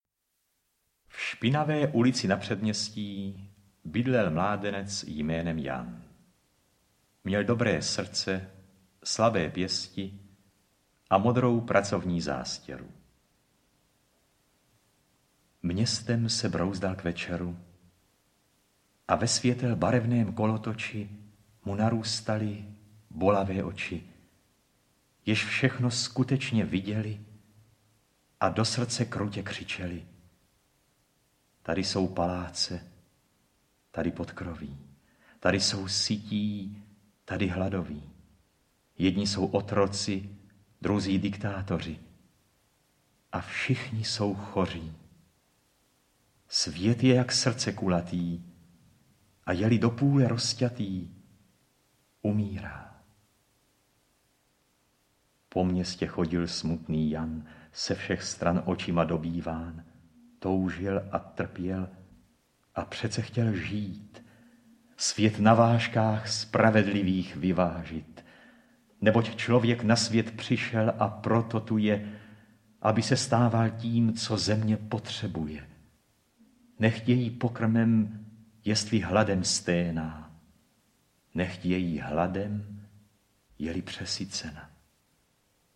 Balady Jiřího Wolkera audiokniha
Ukázka z knihy